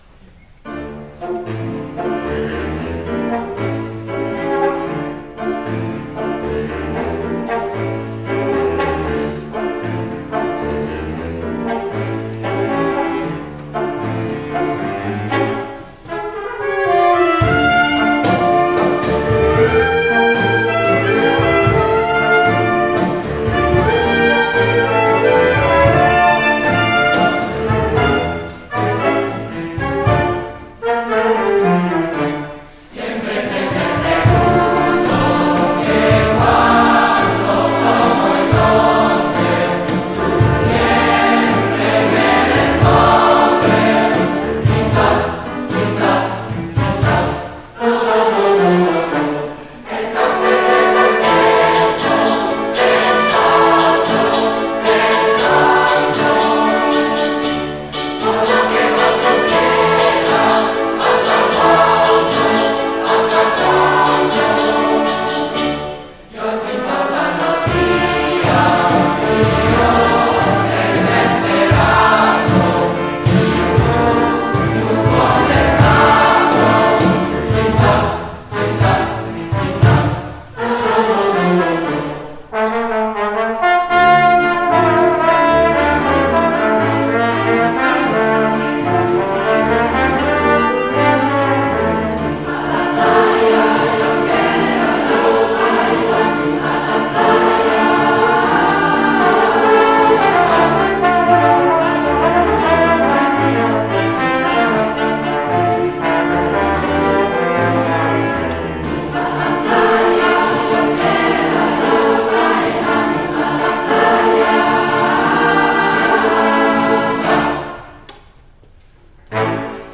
Sommerkonzert 2025 Sing, Sing, Sing!